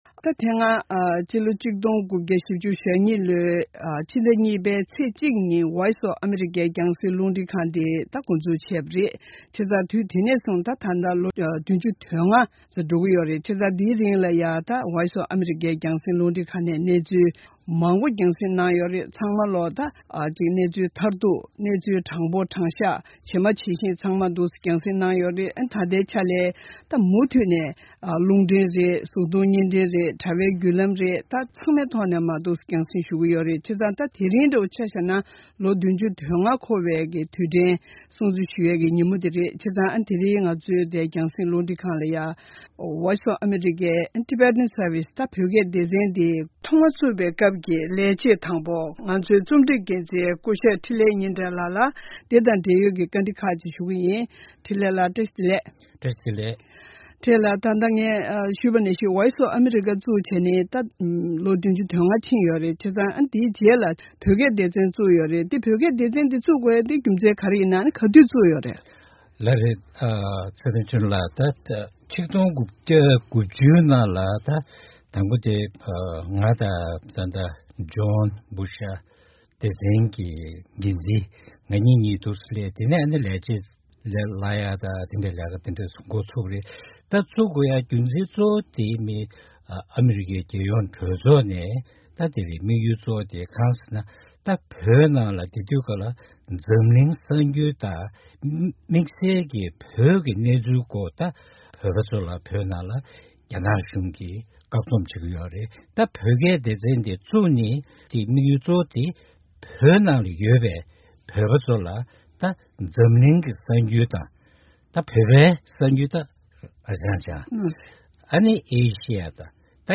དམག་ཆེན་གཉིས་པའི་ནང་ཨ་མི་རི་ཀའི་གཞུང་གིས་དངོས་སུ་མཉམ་ཞུགས་བྱས་ཏེ་གཟའ་འཁོར་བདུན་ཙམ་ཞིག་སོང་བའི་རྗེས་སྤྱི་ལོ་༡༩༤༢ལོའི་ཟླ་༢ ཚེས་༡ ཉིན་ཨ་རིའི་གྲོང་ཁྱེར་ནེའུ་ཡོག་ཏུ་རླུང་འཕྲིན་ལས་ཁང་ཆུང་ངུ་ཞིག་བཙུགས་ཏེ་དུས་ཡུན་སྐར་མ་༡༥ཡི་གསར་འགྱུར་ཞིག་འཇར་མན་ལ་ཐོག་མ་རྒྱང་བསྲིངས་བྱས་པ་ནས་འགོ་འཛུགས་བྱུང་བ་ཞིག་རེད། ད་ཆ་ཨ་རིའི་རླུང་འཕྲིན་ཁང་འདི་བཙུགས་ནས་ལོ་ངོ་༧༥འཁོར་གྱི་ཡོད་པ་དང་། དེང་སྐབས་ཨ་རིའི་རླུང་འཕྲིན་ཁང་ནས་སྐད་རིགས་འདྲ་མིན་དང་བརྒྱུད་ལམ་སྣ་ཚོགས་ཐོག་ནས་འཛམ་གླིང་ས་ཕྱོགས་གང་སར་དྲང་བདེན་ངང་གསར་འགྱུར་རྒྱང་སྲིང་ཞུ་བཞིན་ཡོད་པ་མ་ཟད། སྤྱི་ལོ་༡༩༩༡ལོར་ཨ་རིའི་རླུང་འཕྲིན་ཁང་གི་བོད་སྐད་སྡེ་ཚན་འདི་དབུ་བཙུགས་ཞུས་པ་ནས་བོད་ཕྱི་ནང་གི་བོད་པའི་སྤྱི་ཚོགས་སུ་གསར་འགྱུར་དང་ལས་རིམ་འདྲ་མིན་རྒྱང་སྲིང་ཞུ་བཞིན་ཡོད་པའི་སྐོར་ལ་གནས་འདྲི་ཞུས་པ་ཞིག་གསན་རོགས་གནང་།